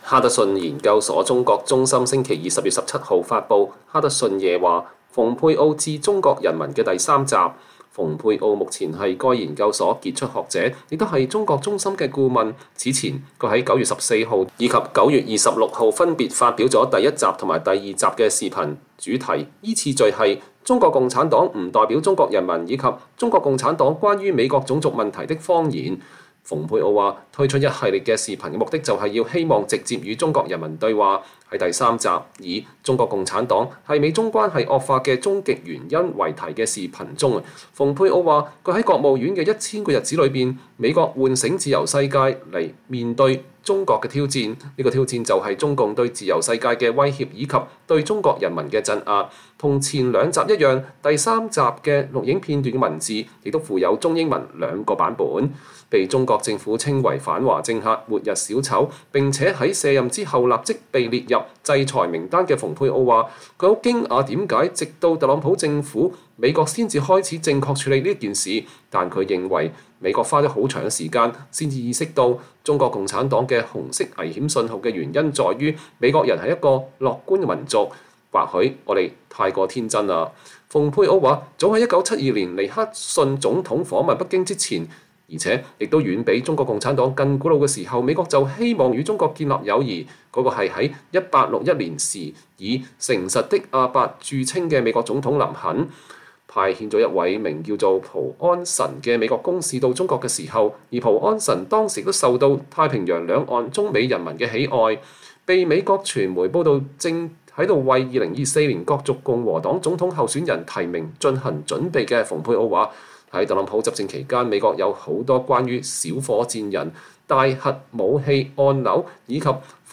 前國務卿蓬佩奧發表致中國人民視頻講話第三集：中共是美中關係惡化終極原因